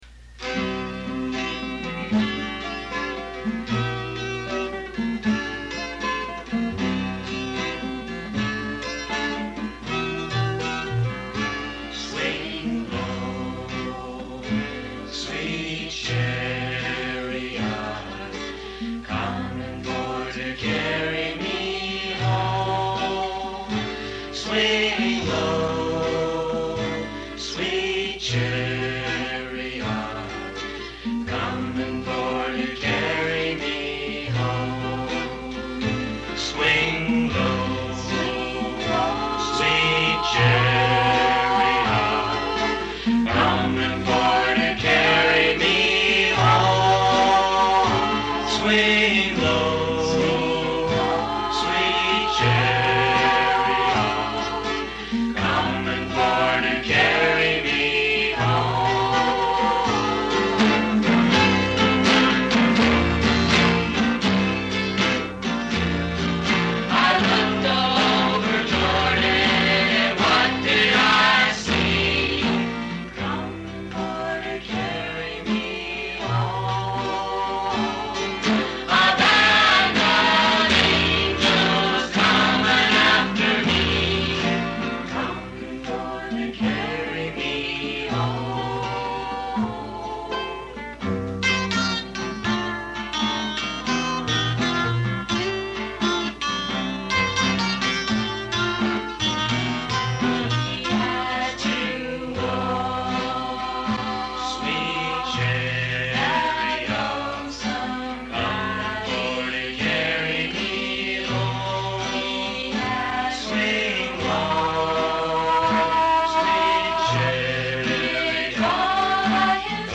Recorded live when we were 15-17 years old